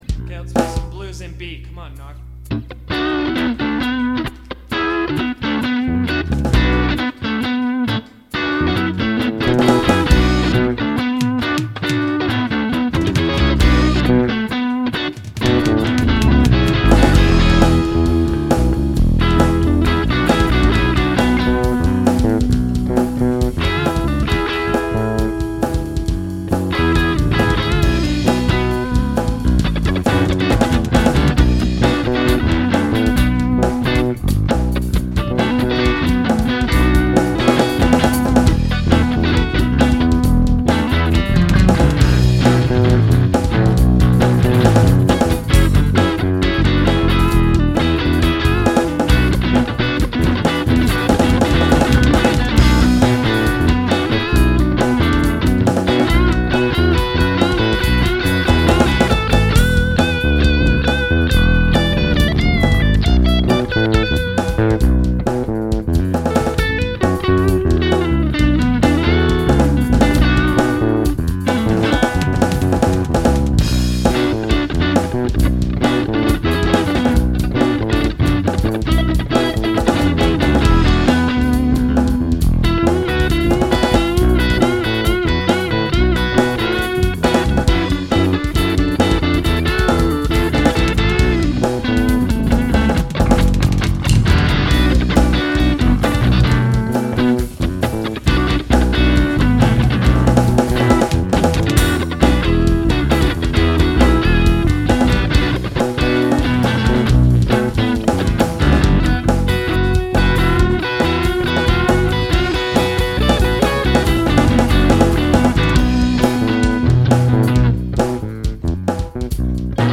Blues , Instrumental